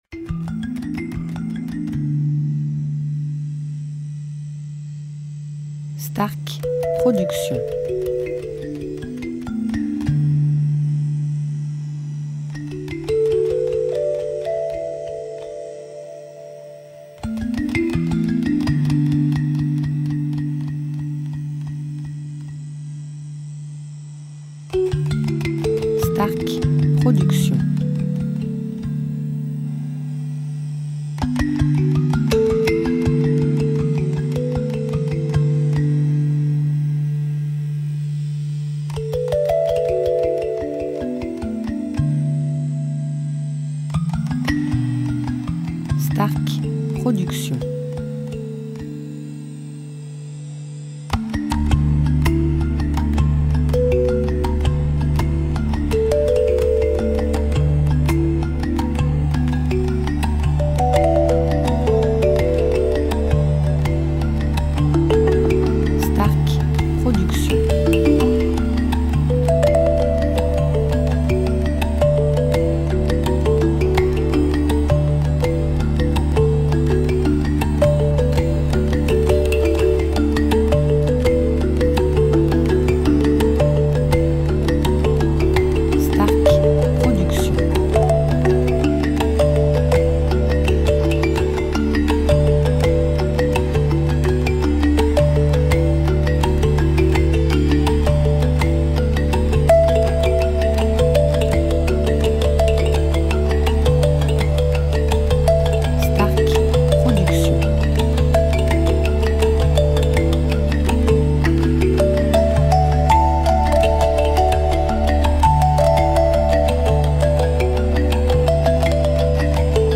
style Relaxation Worldmusic durée 1 heure